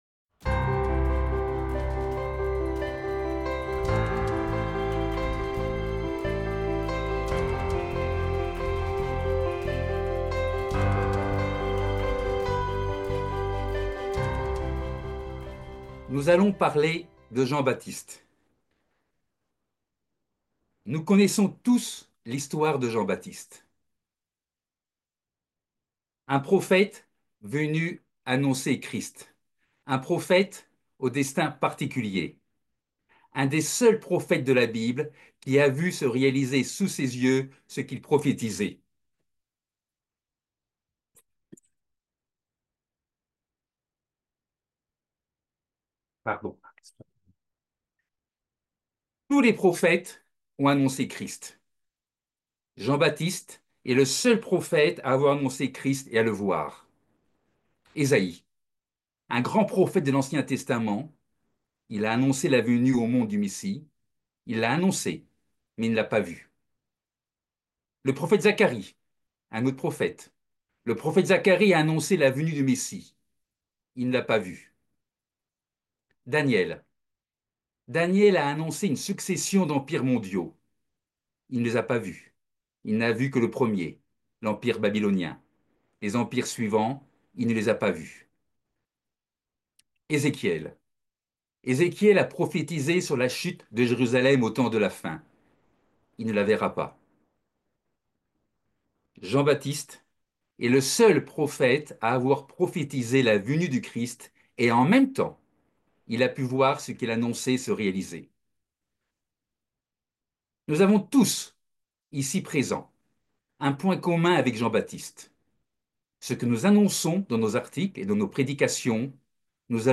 Ce sermon explore la vie et le message unique de Jean-Baptiste, le seul prophète à avoir vu s'accomplir ce qu'il annonçait.
Given in Bordeaux